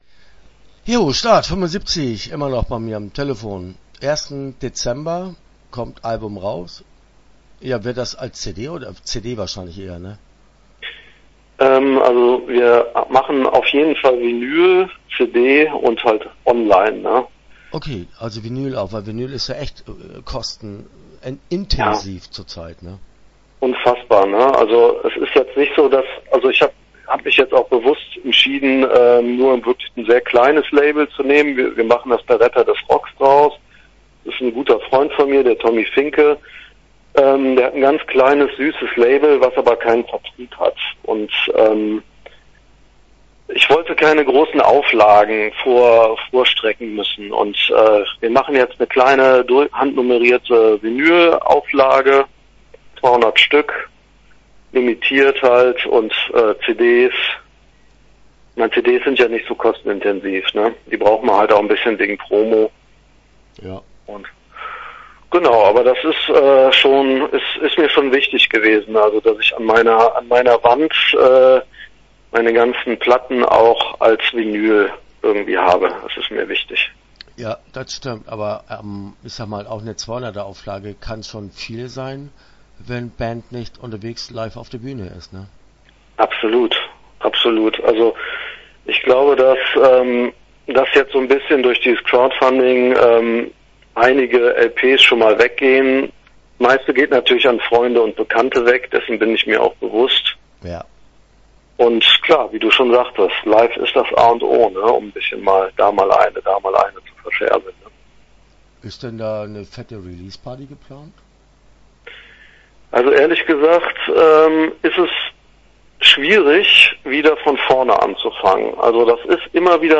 Start 75 - Interview Teil 1 (9:04)